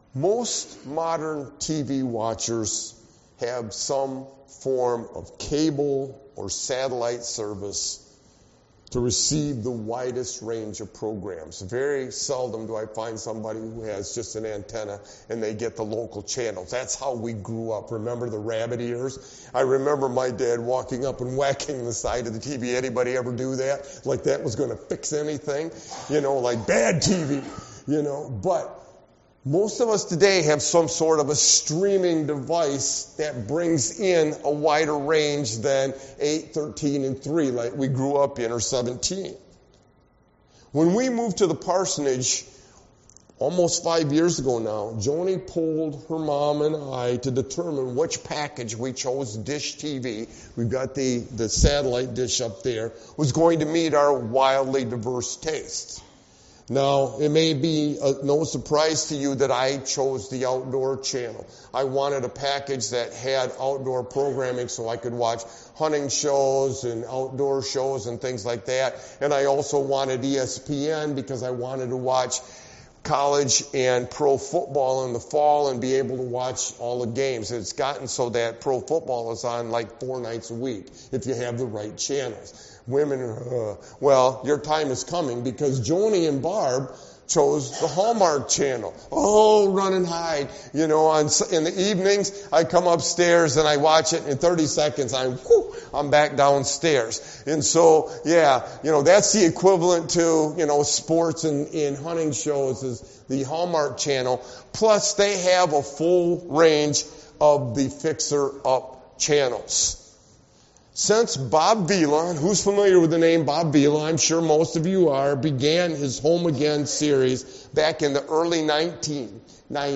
Sermon-Why-a-Sovereign-God-is-crucial-X-32722.mp3